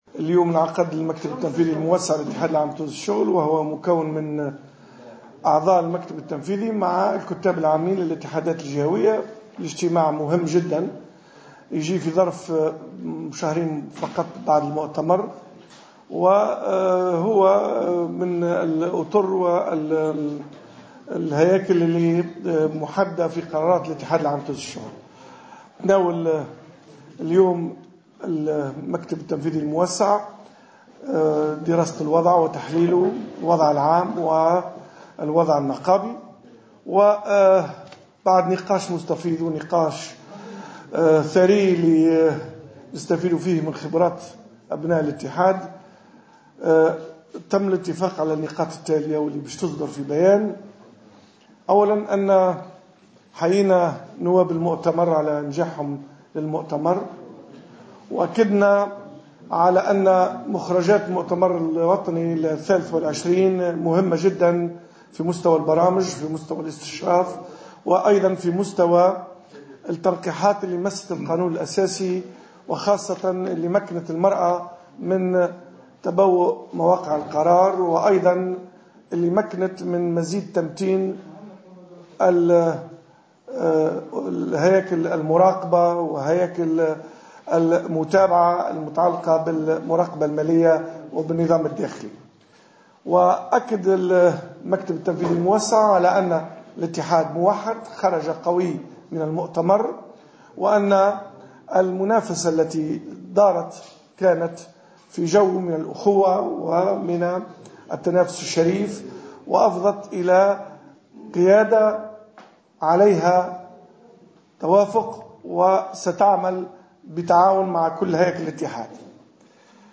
lors d'une conférence de presse tenue à l'issue de la réunion du bureau exécutif élargi de la centrale syndicale